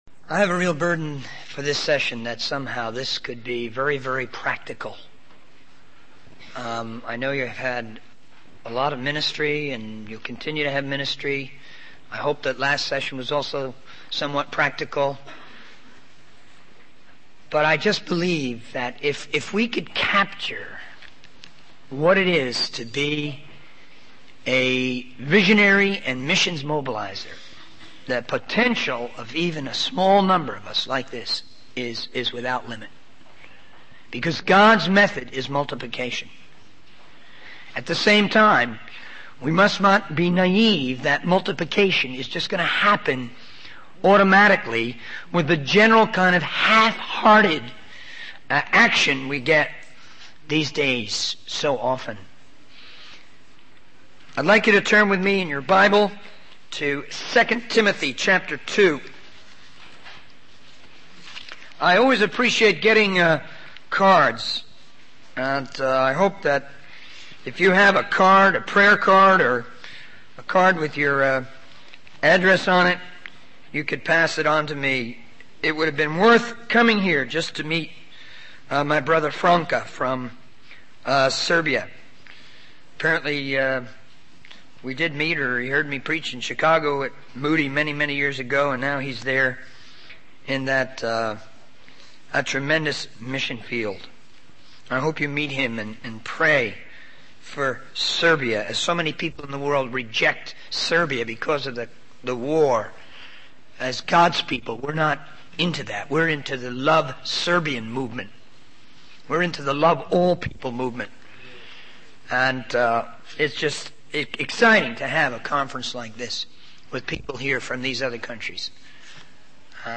The sermon transcript discusses the importance of using video as a tool for spreading the vision for world missions. The speaker emphasizes the need to adapt to new technologies, such as video cassettes, CD-RAM, and the emerging Big Mega Information Highway.